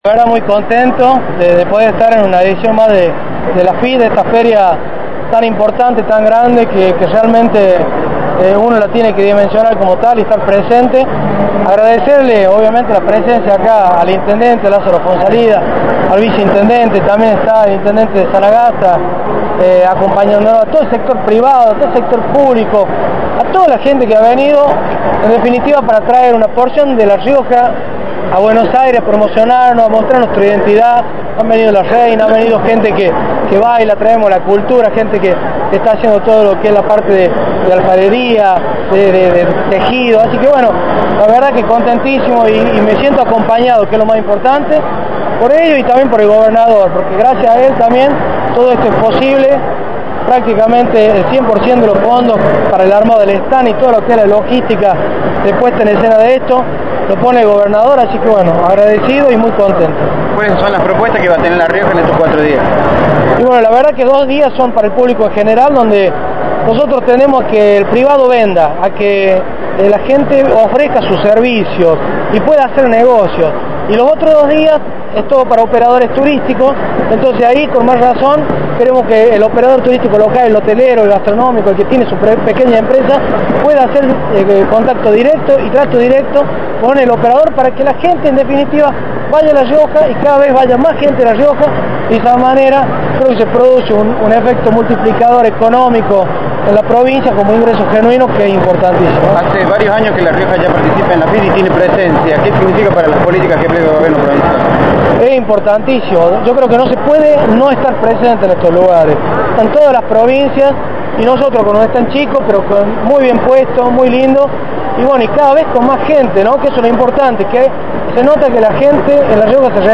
alvaro-del-pino-secretario-de-turismo-de-la-provincia.mp3